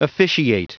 Prononciation du mot officiate en anglais (fichier audio)